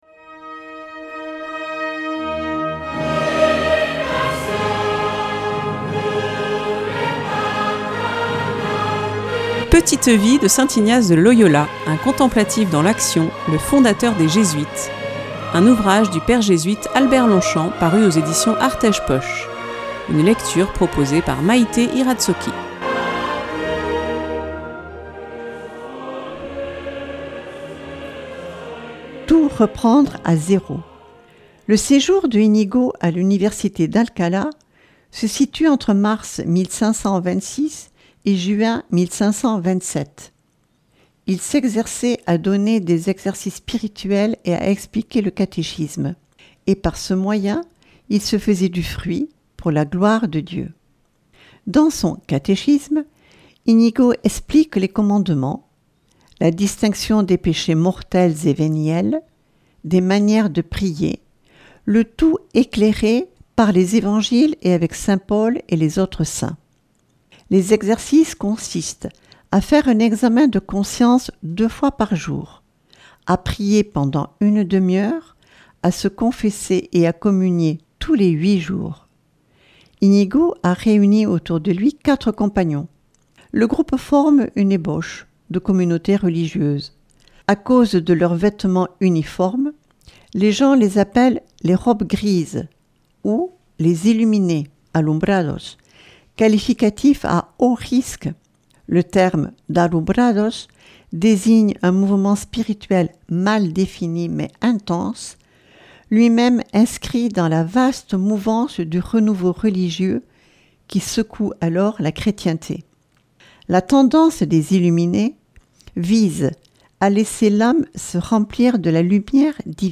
Une lecture